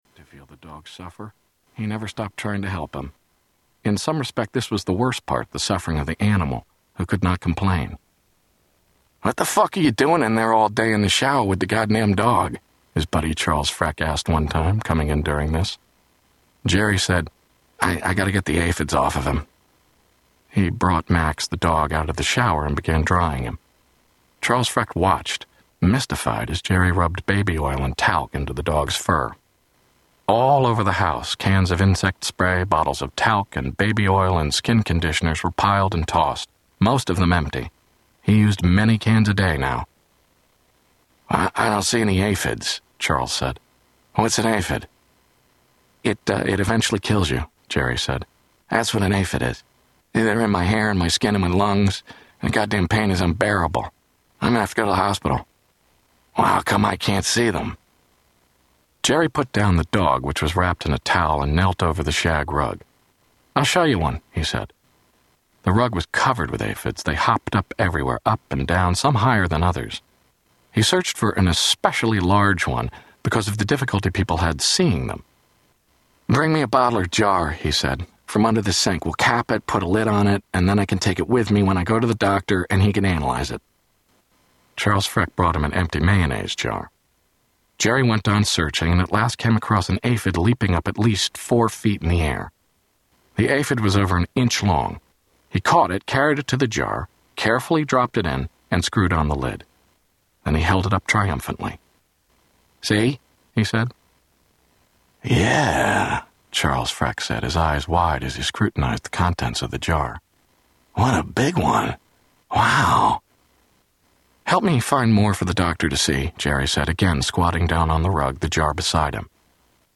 Tags: Philip K Dick Audiobooks Philip K Dick Philip K Dick Audio books Scie-Fi Scie-Fi books